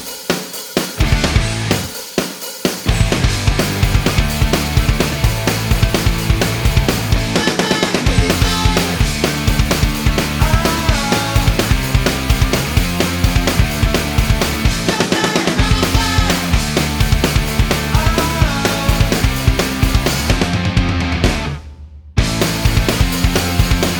no Backing Vocals with TV Clips Rock 3:25 Buy £1.50